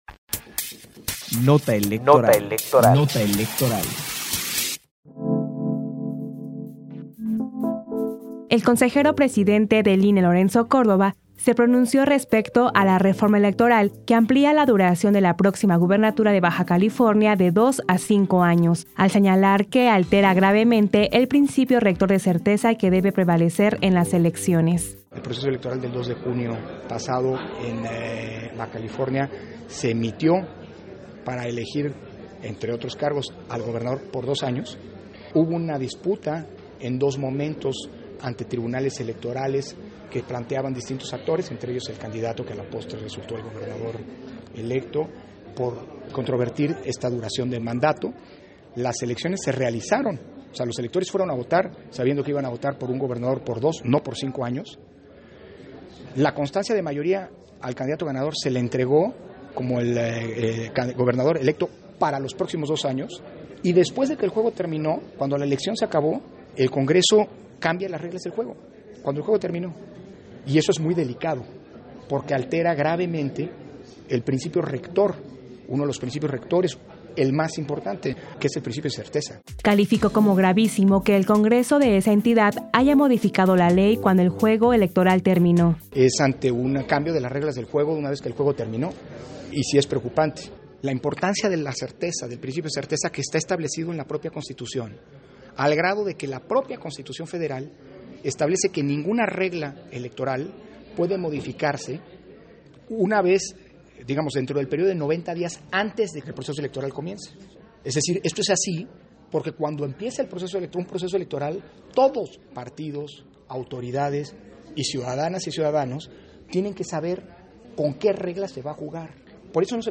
Posicionamiento del Consejero Presidente del INE, Lorenzo Córdova Vianello, respecto a la ampliación de mandato en Baja California